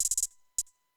Closed Hats
HATZ.wav